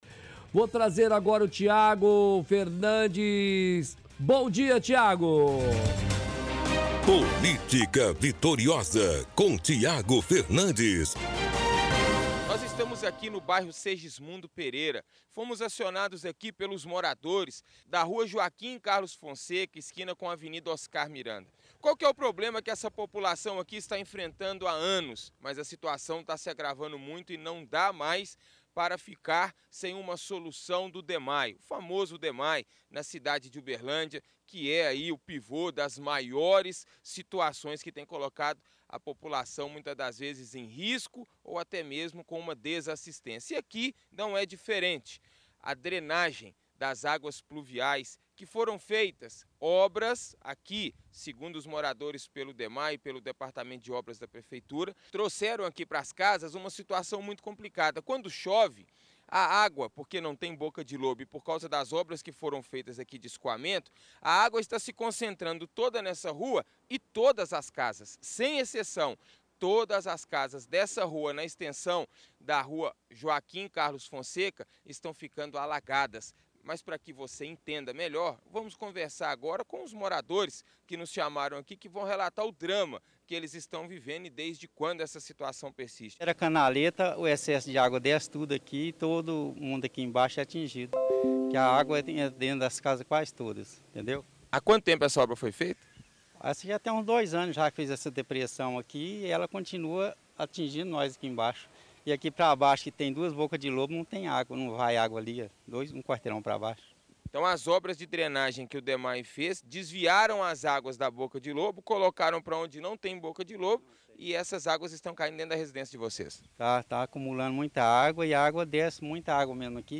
– Transmissão de áudio da reportagem de hoje do Chumbo Grosso.